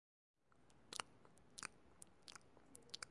音频I " Pinguino cae
Tag: 环境 atmophere 记录